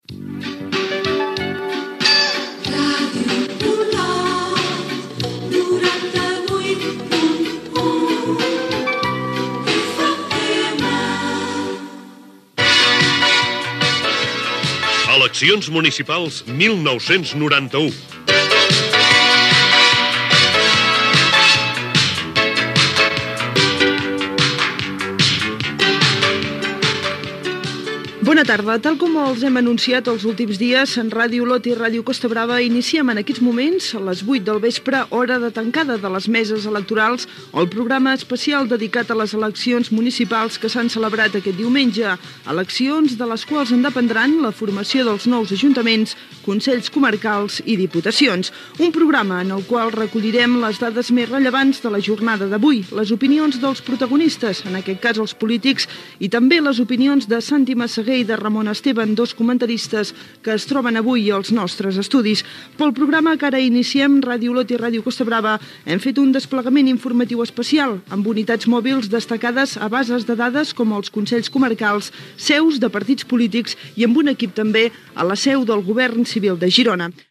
Indicatiu de l'emissora, careta del programa, presentació del programa.
Informatiu